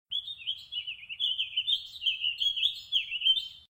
Bird 1.ogg